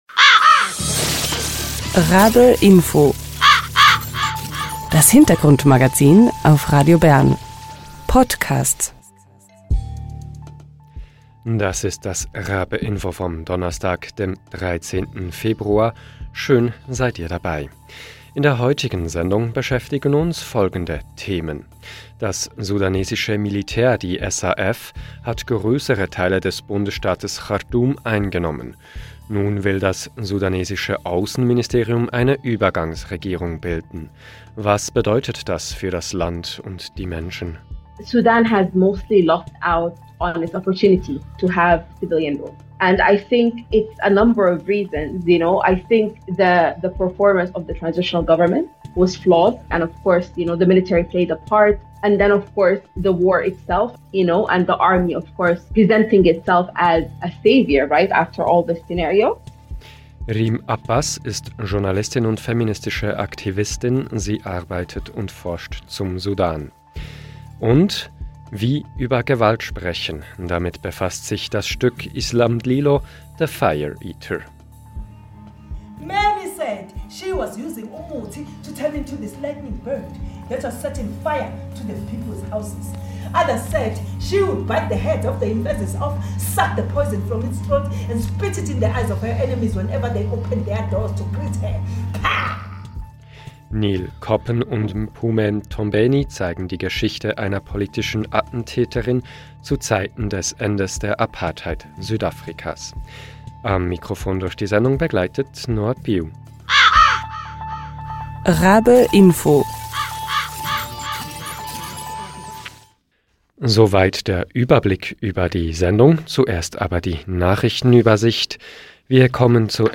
Im Gespräch mit RaBe-Info schätzt sie die aktuelle Situation im Sudan ein.